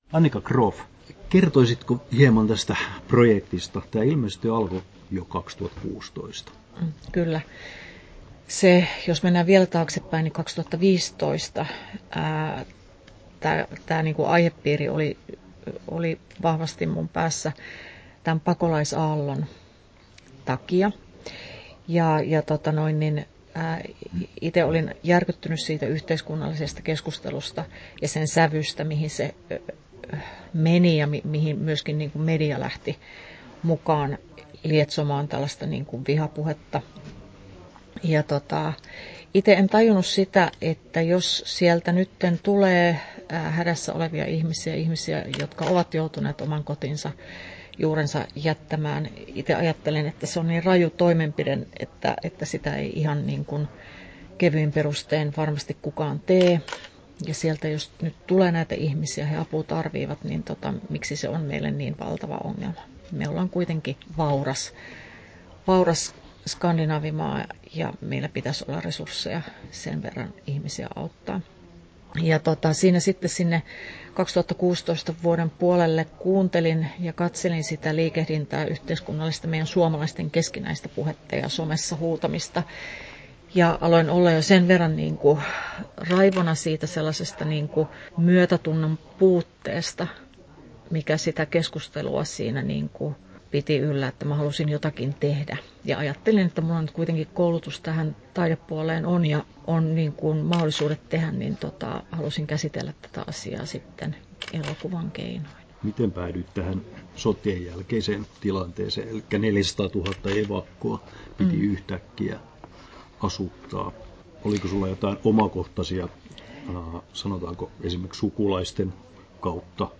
Haastattelut